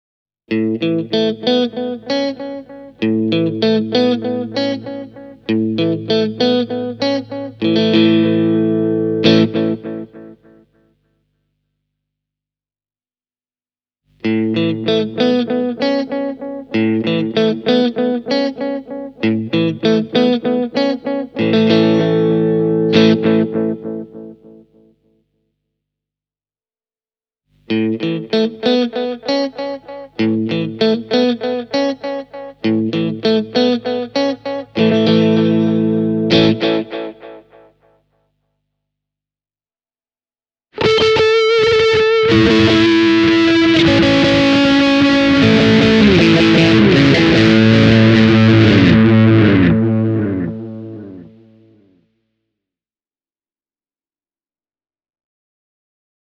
Analog on Mooerin versio vanhasta analogisesta viivepedaalista, Real Echo on nykyaikainen digitaalinen delay-efekti, ja Tape Echo matkii vanhan nauhakaiun soundia.
Analog-moodi soi juuri oikealla tavalla suttuisesti, kun taas Real Echo tarjoaa orgaanisen lämmintä viive-efekiä.
Esimerkki alkaa Analog-vaihtoehdosta, sitten tulee Real Echo, ja sitten Tape – viimeisinä toinen Analog-viivellä soitettu pätkä: